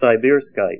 Help on Name Pronunciation: Name Pronunciation: Sibirskite + Pronunciation
Say SIBIRSKITE